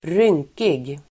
Ladda ner uttalet
rynkig adjektiv, wrinkled Uttal: [²r'yng:kig] Böjningar: rynkigt, rynkiga Synonymer: fårad, skrynklig, veckad Definition: som har många rynkor Exempel: ett rynkigt ansikte (a wrinkled face) wrinkly , rynkig